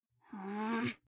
suck1-3.mp3